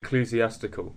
Ääntäminen
UK : IPA : [ə.ˈkliː.zi.ˌæs.tɪ.kəl]